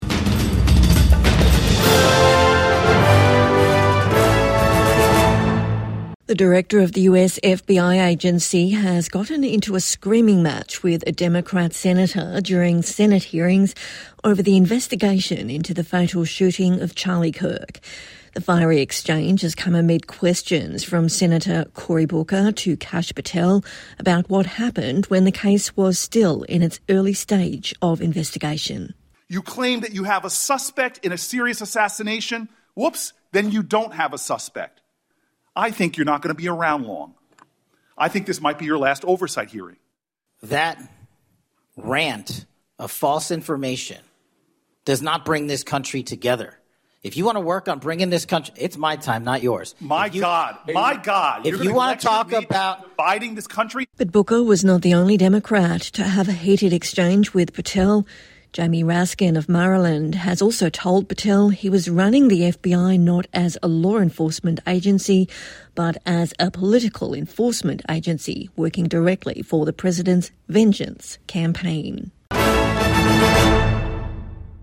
Senator gets into shouting match with Kash Patel over FBI conduct